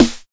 81 SNARE 3.wav